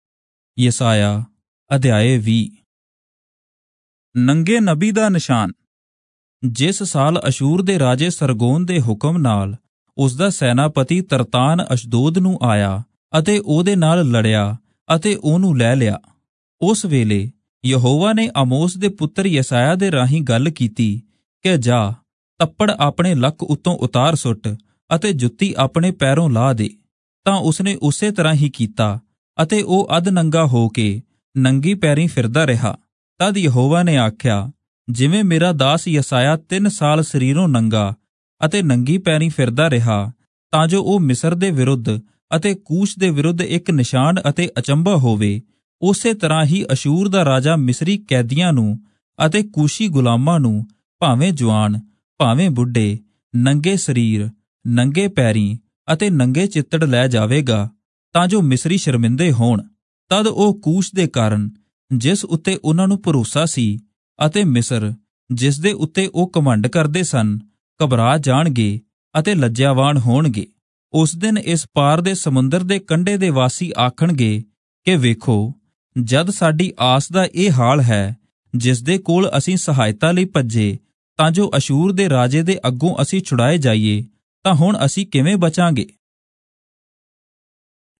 Punjabi Audio Bible - Isaiah 20 in Irvpa bible version